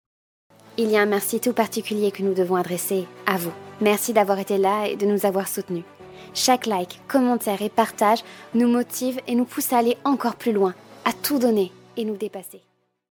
Voix jeune/douce/posée
3 - 30 ans - Mezzo-soprano